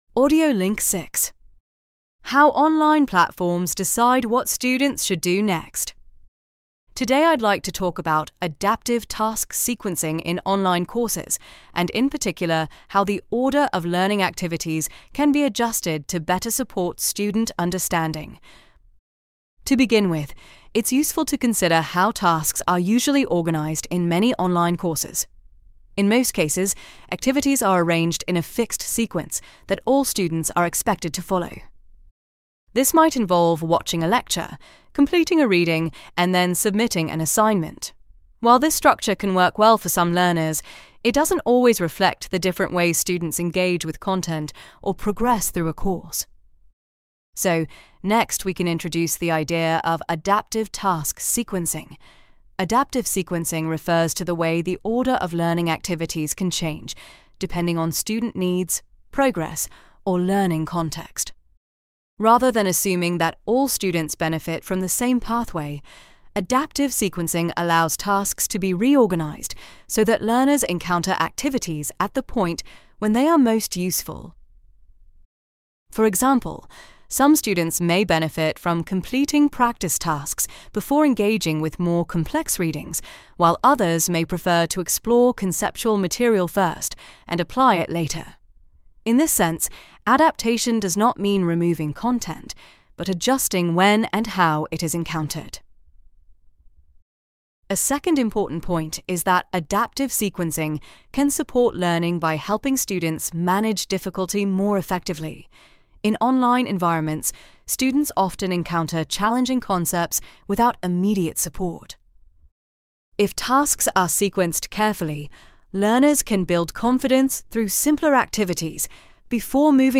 Listen to the following lecture and take notes how the lecture is organised, the signposting language you identify and what the signposting language signals.